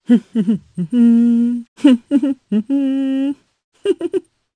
Morrah-Vox_Hum_jp.wav